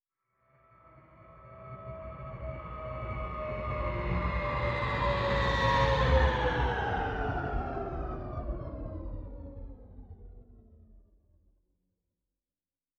Distant Ship Pass By 3_3.wav